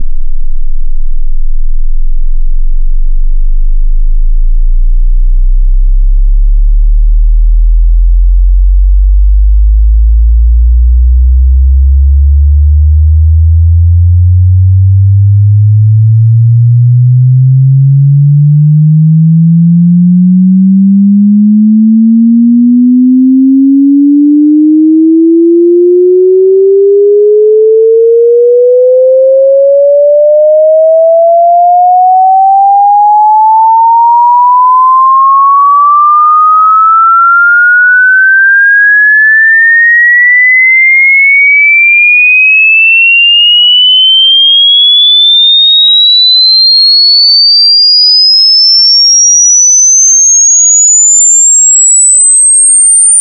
sin 16 Hz - 20 kHz, L=R